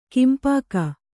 ♪ kinpāka